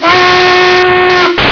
AMERICAN WHISTLES
n_whooter.wav